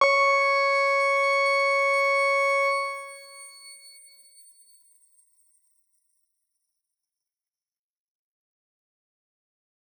X_Grain-C#5-mf.wav